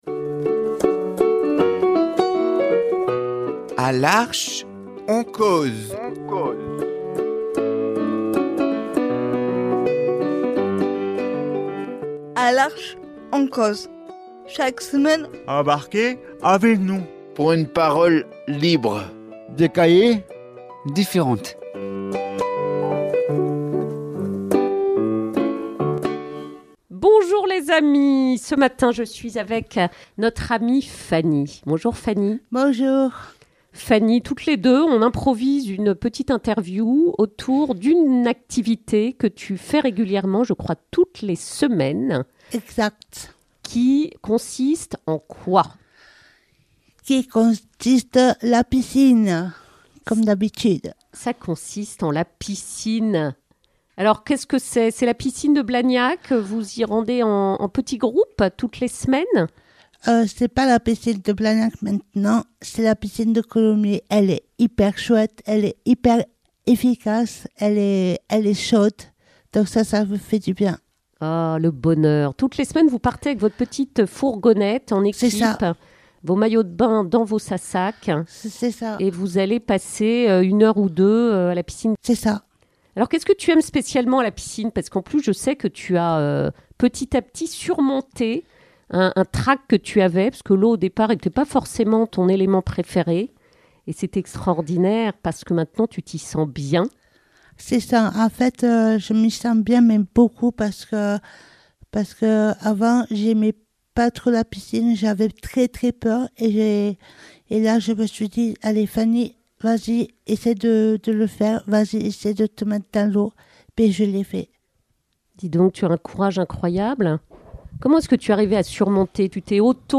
Dans cette interview imprévue